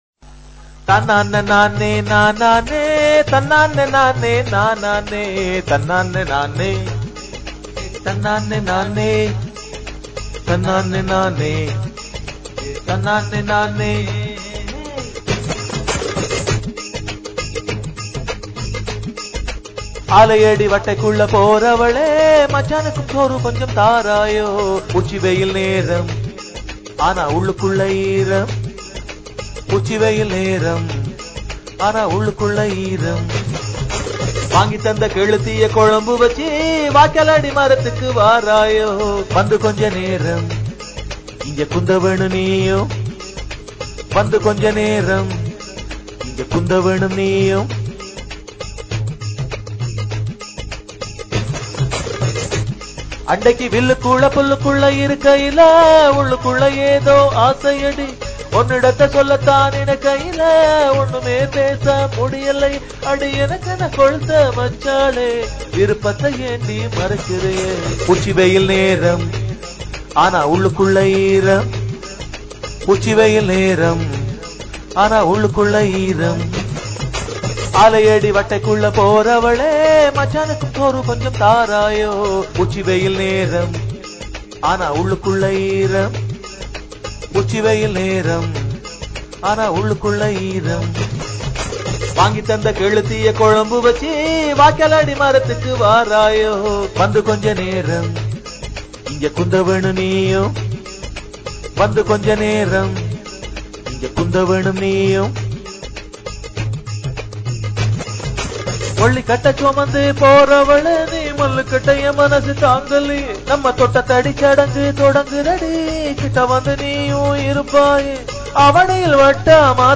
கிராமத்து பாட்டு,